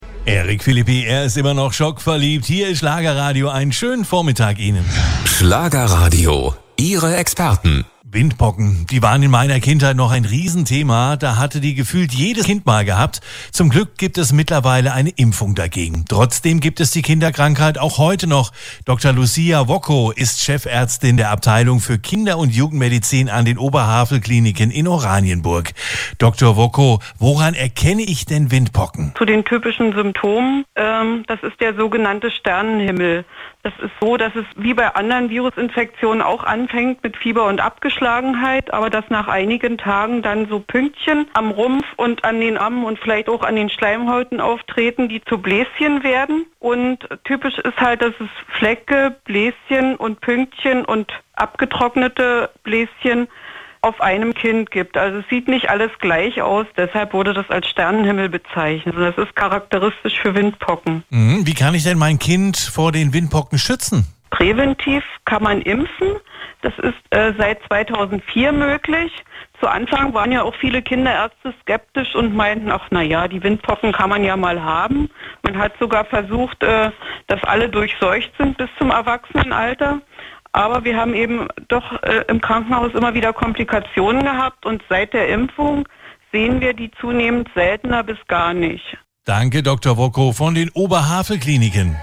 im Interview bei Schlagerradio.